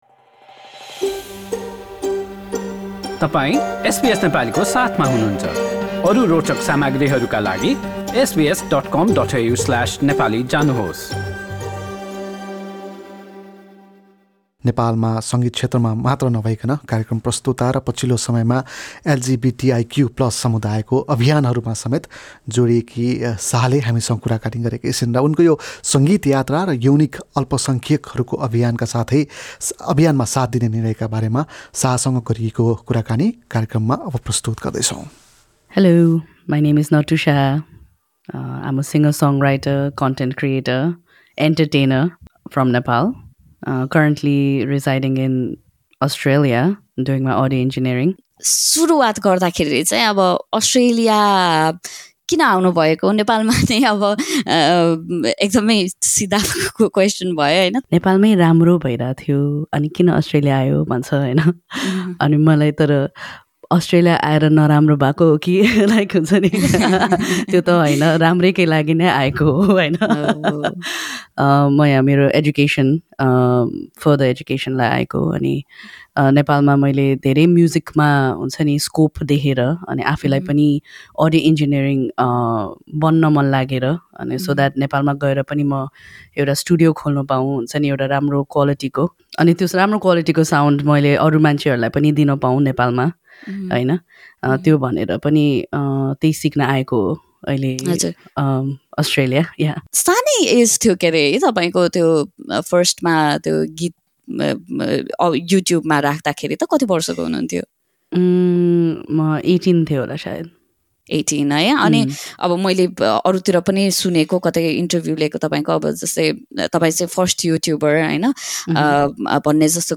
एसबीएस नेपालीसँग गरेको कुराकानी सुन्नुहोस।